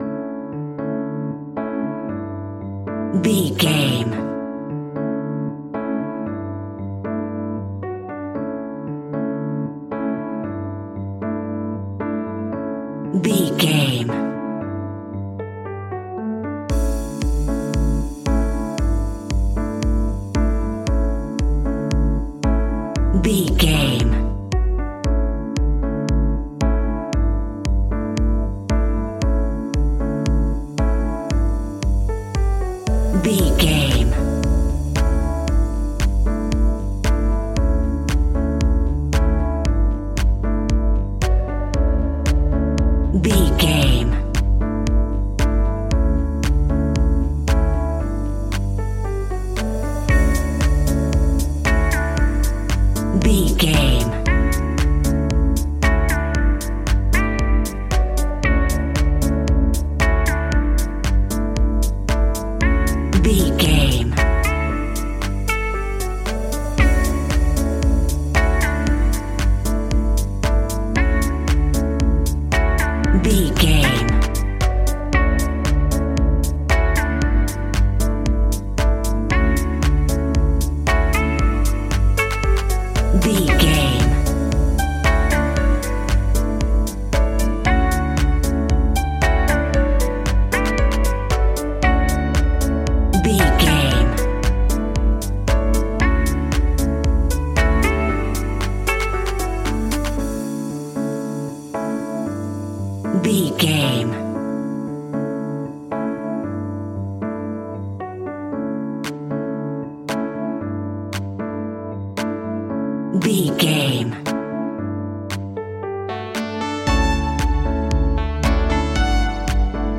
Ionian/Major
D
uplifting
energetic
bouncy
piano
electric piano
drum machine
synthesiser
electro house
funky house
synth leads
synth bass